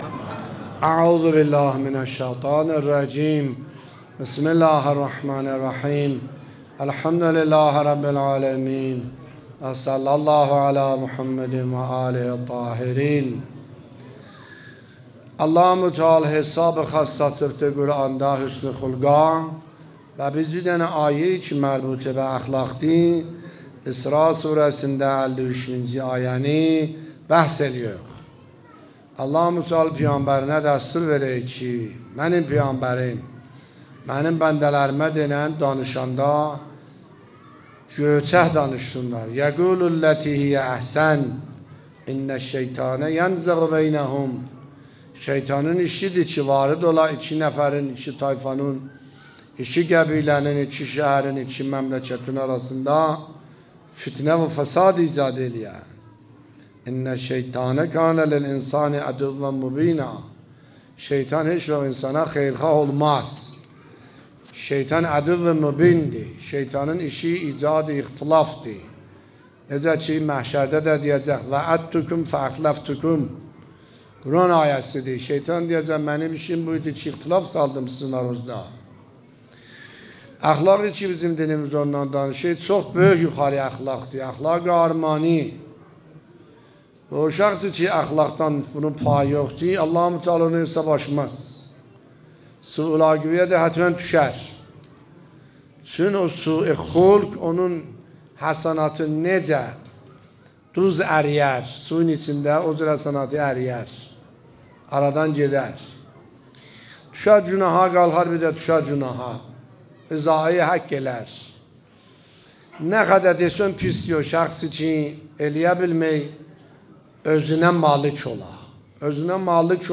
نکات برگزیده تفسیری آیه 53 سوره مبارکه اسراء در بیان دلنشین آیت الله سید حسن عاملی در مسجد مرحوم میرزا علی اکبر در ششمین شب رمضان المبارک 1402 به مدت 20 دقیقه